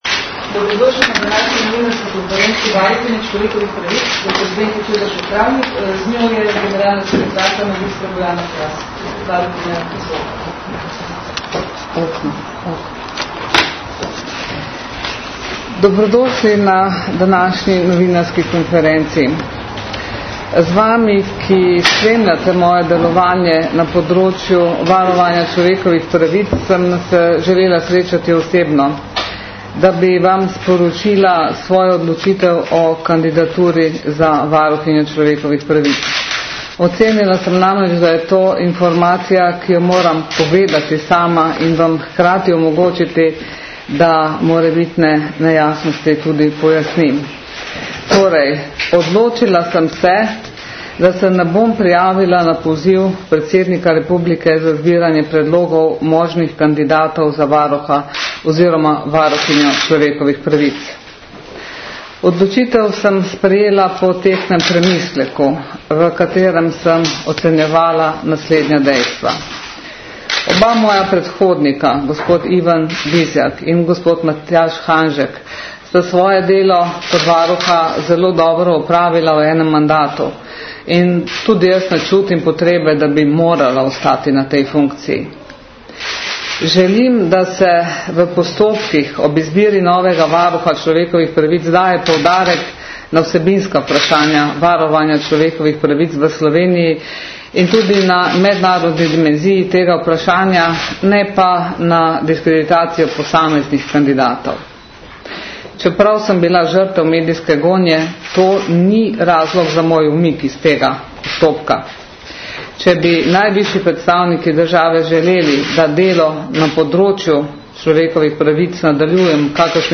Varuhinja človekovih pravic dr. Zdenka Čebašek - Travnik je na današnji novinarski konferenci javnost seznanila z odločitvijo, da se ne bo prijavila na poziv predsednika republike za zbiranje predlogov možnih kandidatov za varuha oziroma varuhinjo človekovih pravic.
Podrobnosti novinarske konference lahko izveste s poslušanjem zvočnega posnetka.